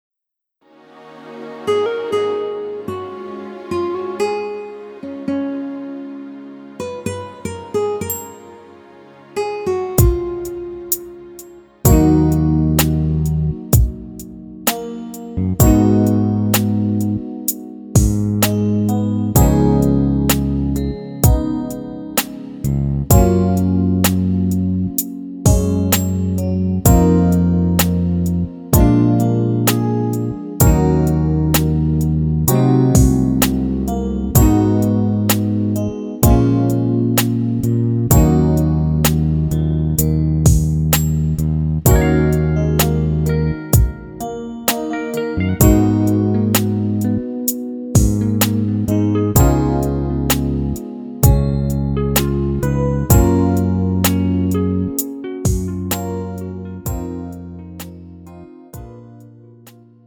음정 원키 4:16
장르 가요 구분 Pro MR